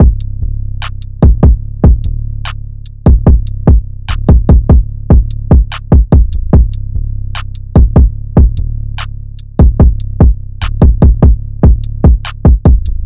EN - Loop II (147 BPM).wav